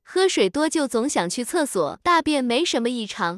tts_result_7.wav